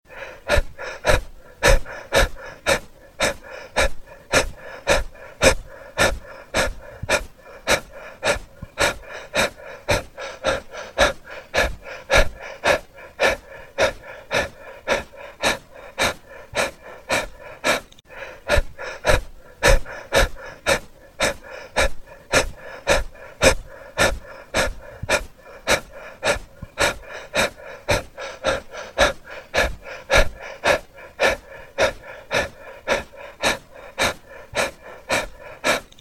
ハァハァハァハァ。